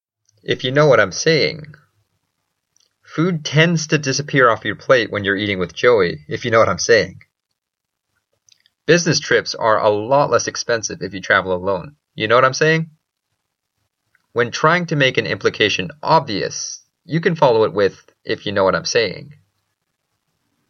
英語ネイティブによる発音は下記のリンクをクリックしてください。
ifyouknowwhatimsaying.mp3